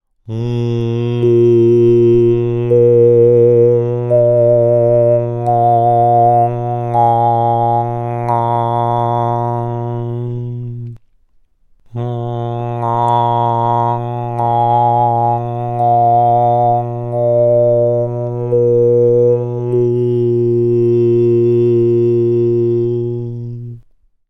Hörprobe Obertonleiter Nr. 4 gesungen von H3 bis H 8 mit Gong-Technik
Bei den hier zu hörenden Aufnahmen bleibt der Grundton jeweils stabil auf einer sogenannten Grundton-Frequenz
obertonleiter-gong-technik_h3-h8.mp3